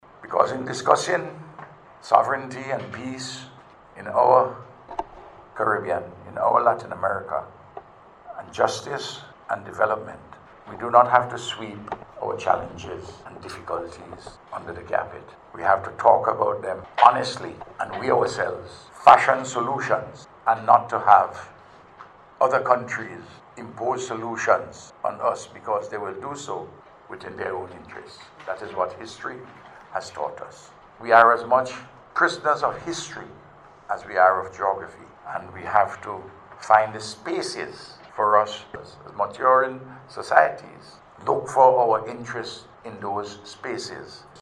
Prime Minister Dr. Ralph Gonsalves made the statement at a ceremony to mark the 214th anniversary of Venezuela’s independence, on Thursday July 3rd.